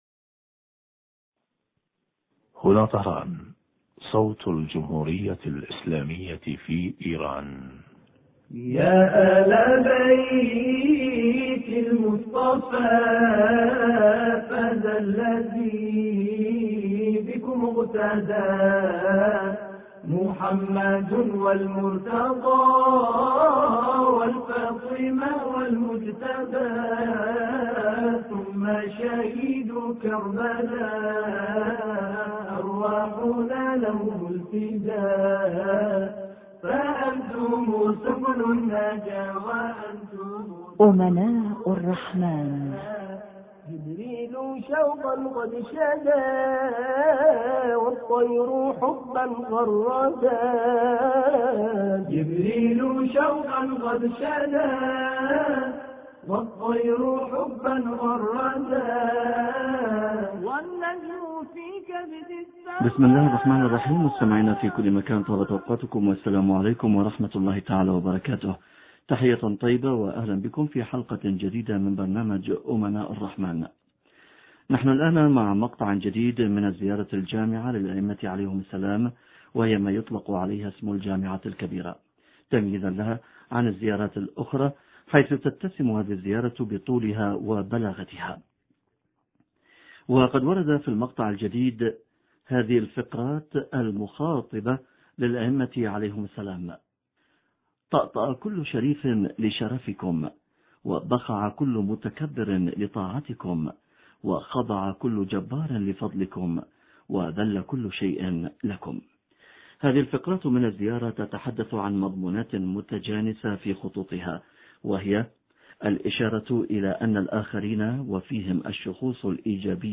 حوار
نستمع معاً للاتصال الهاتفي التالي